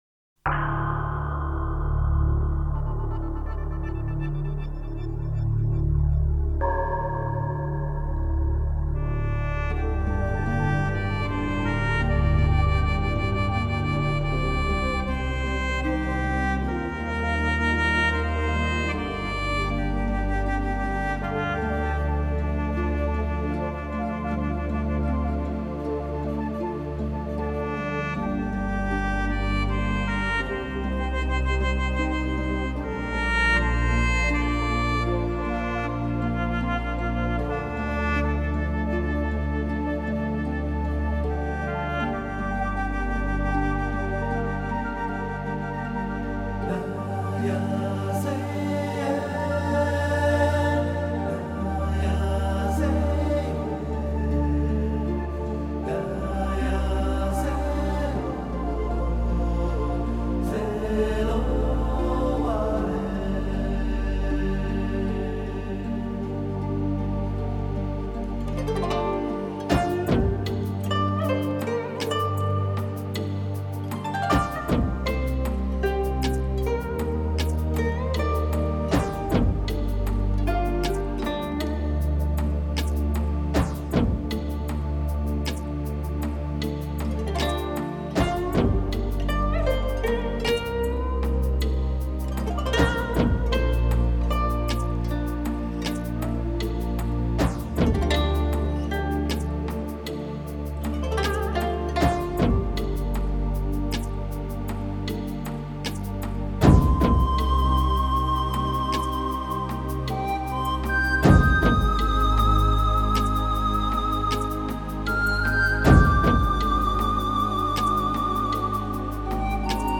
演出：笙、排箫、筝......等
以笙开始，随后古筝奏出了主旋律，然后排箫道出了一种悠远，
男声似有似无地的从远处飘来，这是一种什么感觉？